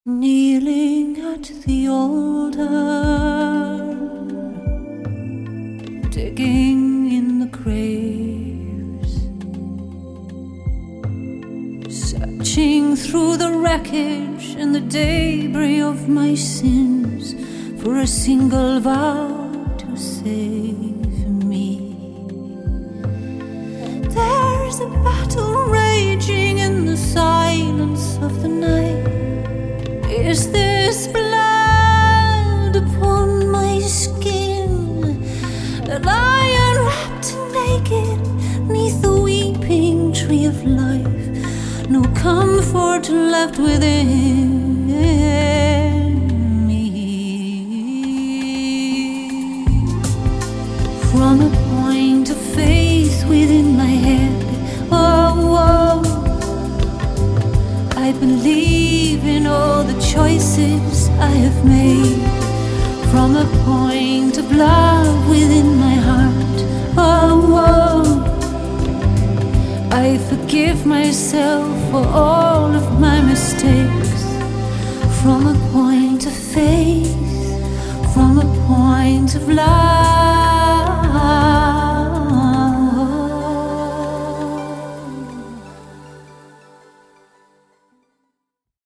Performed Bass on
a powerful song of self-acceptance.